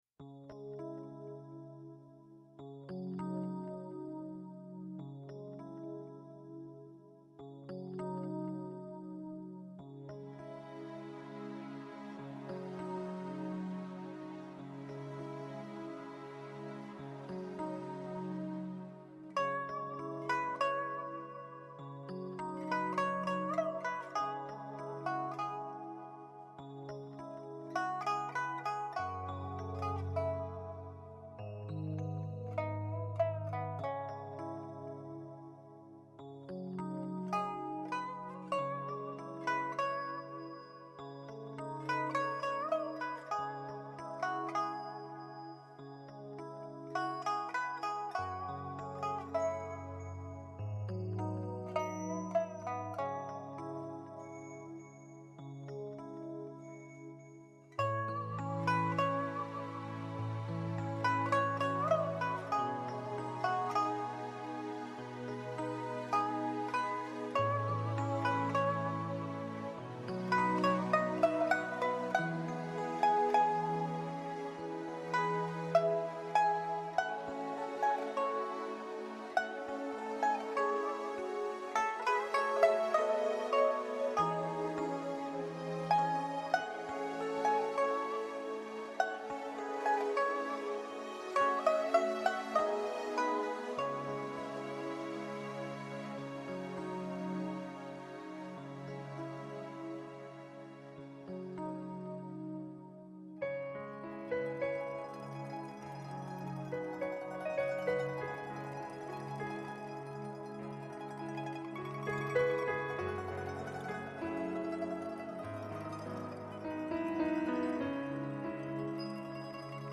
Category:Traditional instrument Pipa Nusic